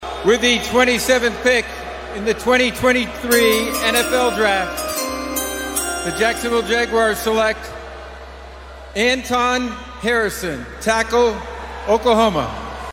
The Draft is taking place in front of Kansas City’s Union Station downtown.
Here is how it the Sooner pick sounded.